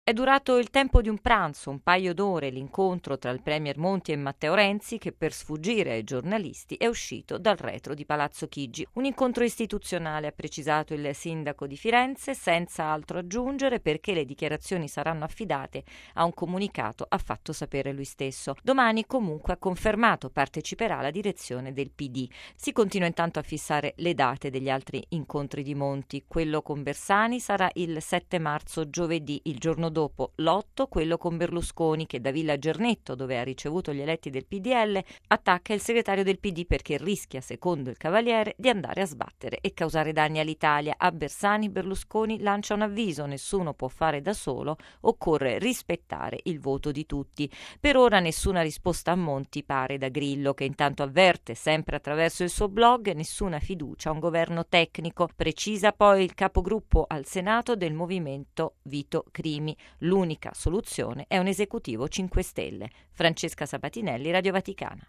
Il presidente Napolitano esclude l’anticipo della convocazione delle Camere, che resta il 15 marzo. A Palazzo Chigi lungo incontro tra Monti e Renzi, mentre Berlusconi avvisa Bersani: rischia di andare a sbattere. Da Grillo no anche al governo tecnico. Servizio